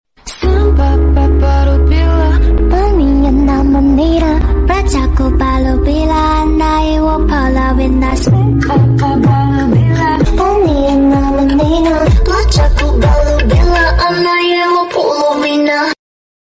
Kanae , Shinobu , Kanao , Aoi , Nezuko y Mitsuri cantando